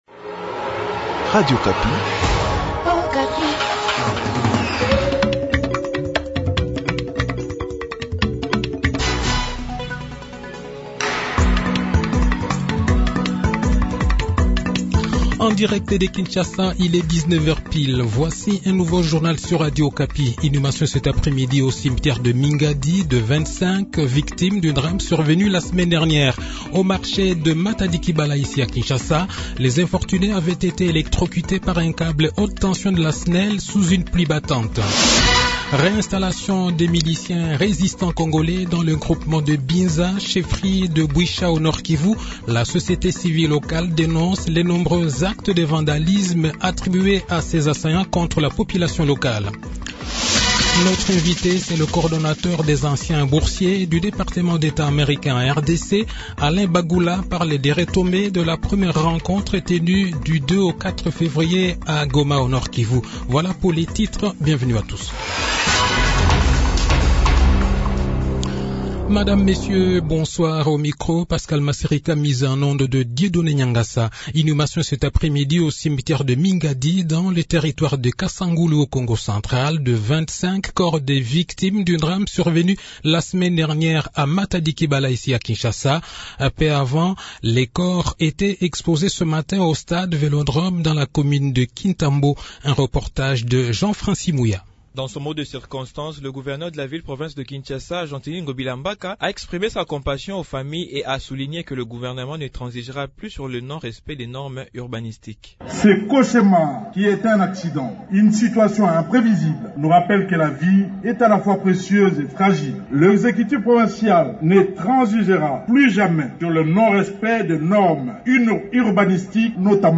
Le journal de 18 h, 11 février 2022